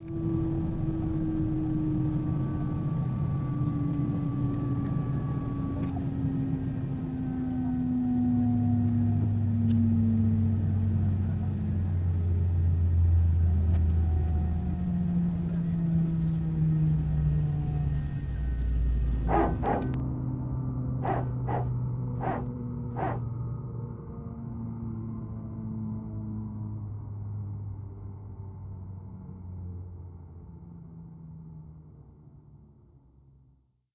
A320_cockpit_shutdown.wav